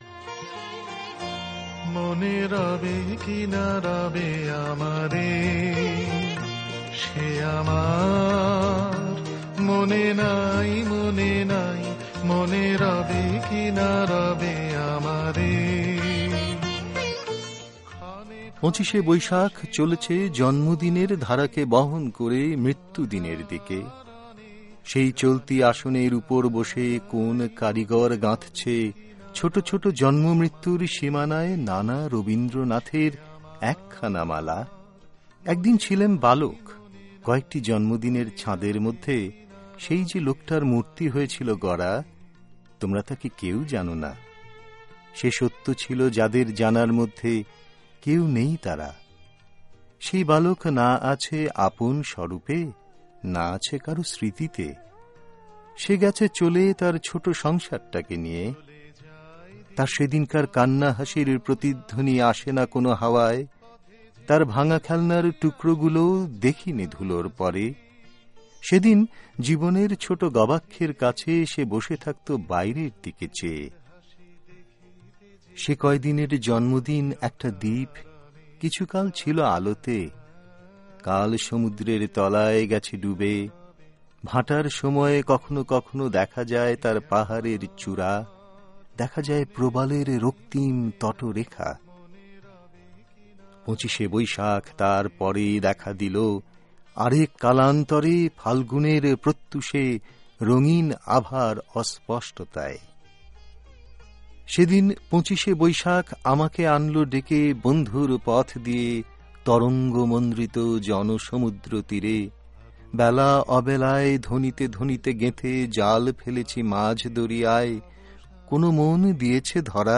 তাঁর জন্মদিনে তাঁর গানে ও কবিতায় আমাদের অকিঞ্চিৎকর একটি নিবেদন
সংগীত পরিবেশনায় কবীর সুমন।